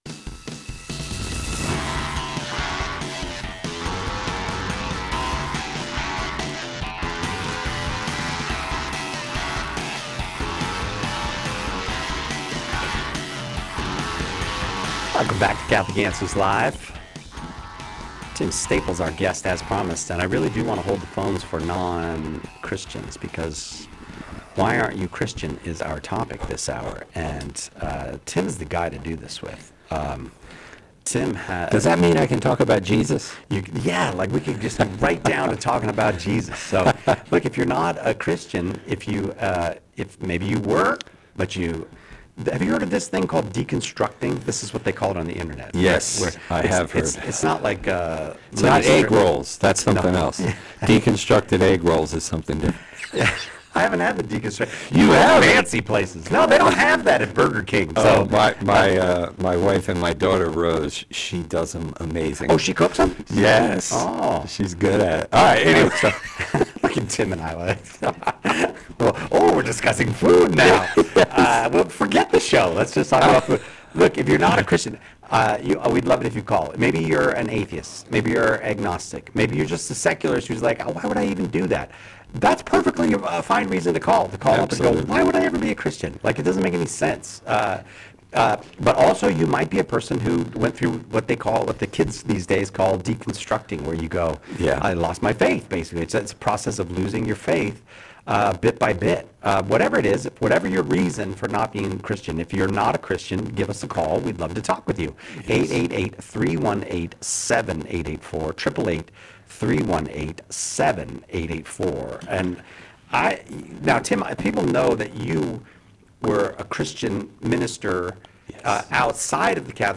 In this episode we ask callers why they are not Christian, and we answer any questions they have about the Catholic faith.